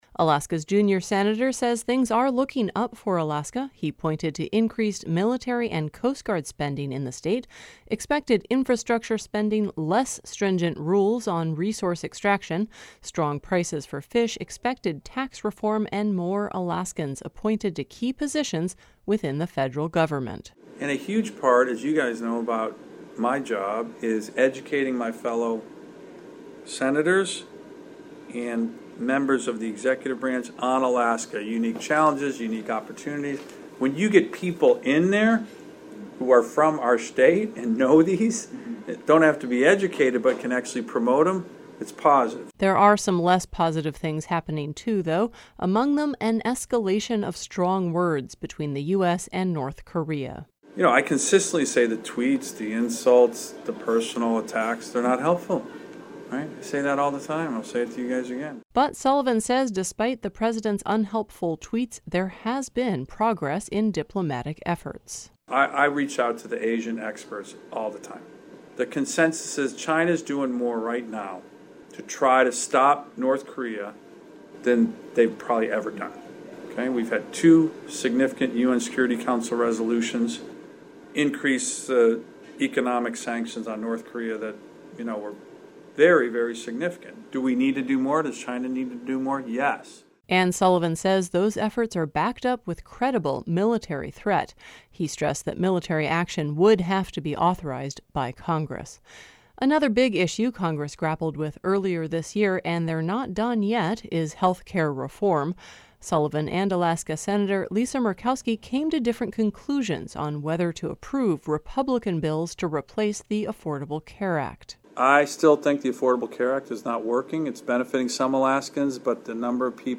Alaska Sen. Dan Sullivan is optimistic about the future for Alaska and the nation. He spoke briefly with members of the local media during a visit to Ketchikan on Oct. 11.